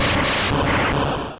Cri d'Électrode dans Pokémon Rouge et Bleu.